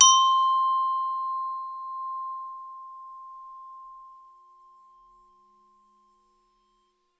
glock_medium_C5.wav